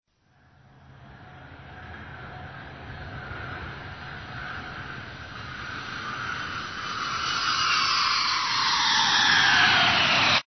دانلود آهنگ افکت سه بعدی هواپیما از افکت صوتی حمل و نقل
جلوه های صوتی
دانلود صدای افکت سه بعدی هواپیما از ساعد نیوز با لینک مستقیم و کیفیت بالا